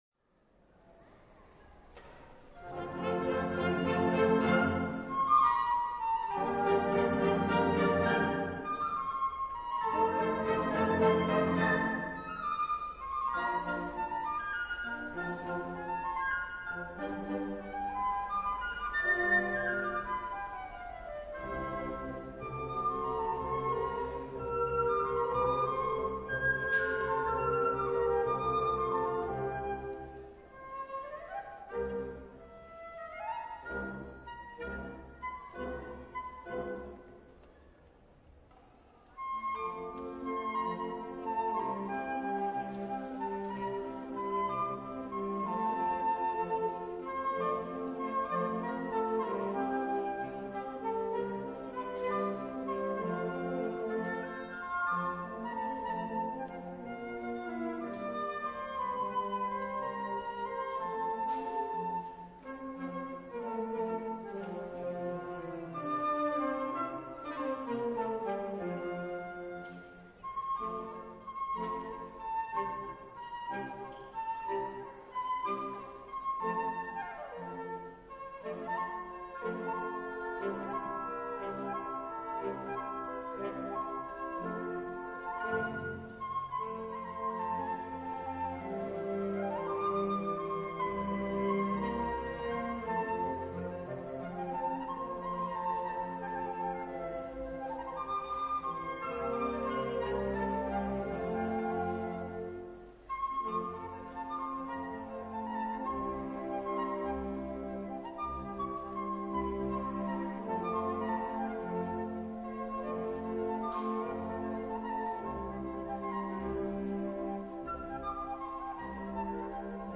Dopo il Pastore Svizzero per flauto e banda